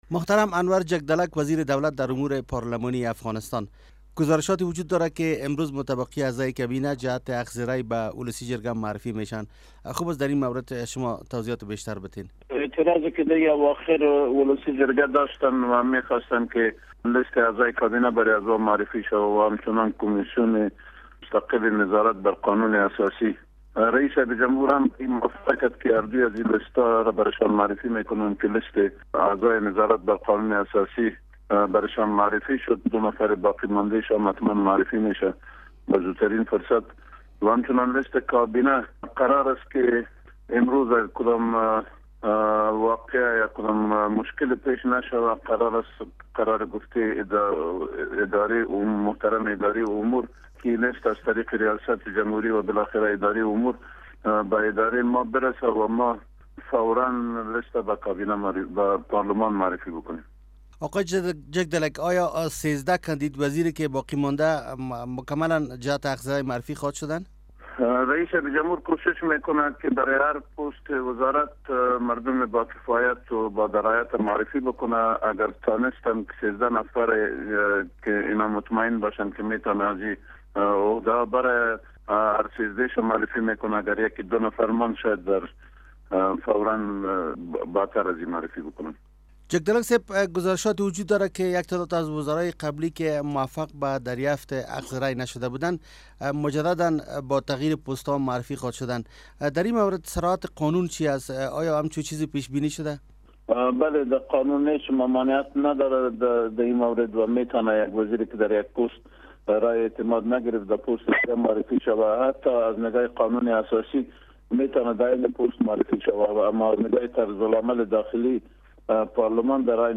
مصاحبه با انور جگدلک وزیر دولت در امور پارلمانی در مورد معرفی اعضای باقی مانده کابینه به ولسی جرگه